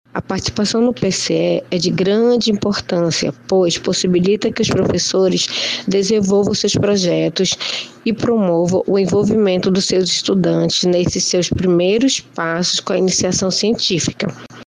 SONORA01_PROGRAMA-CIENCIA-NA-ESCOLA.mp3